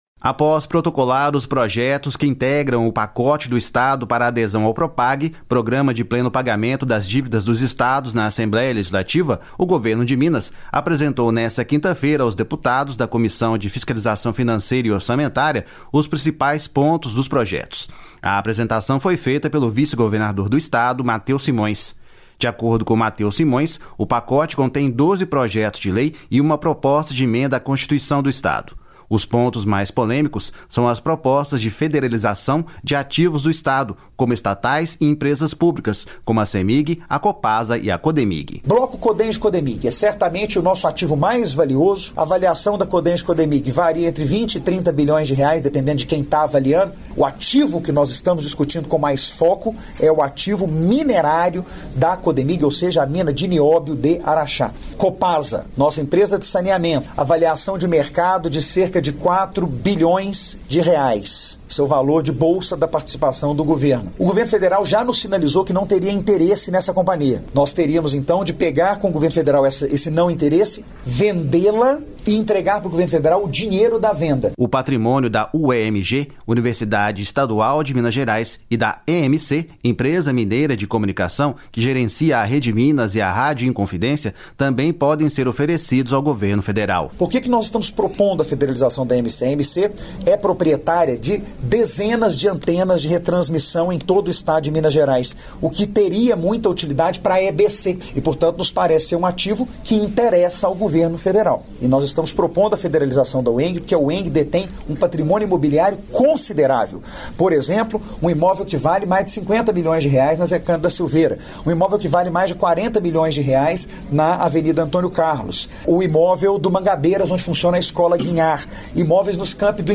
A apresentação foi feita pelo vice-governador Mateus Simões.